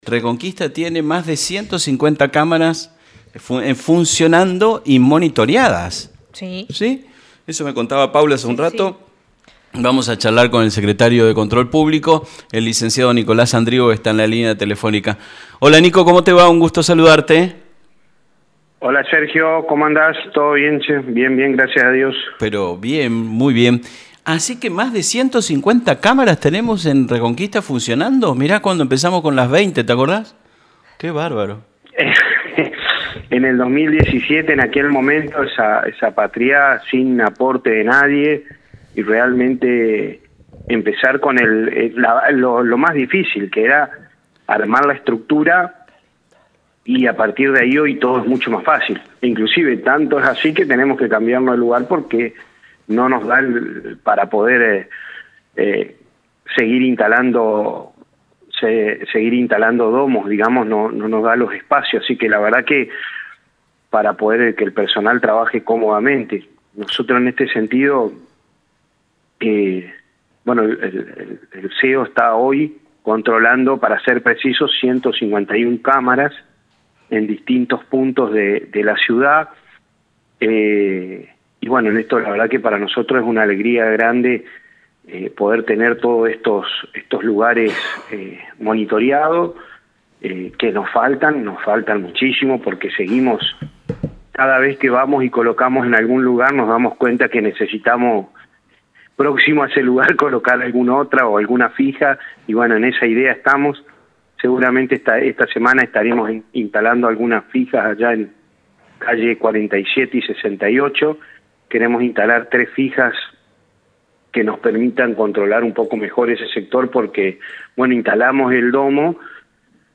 Escucha la nota con el Secretario Nicolás Sandrigo para más detalles: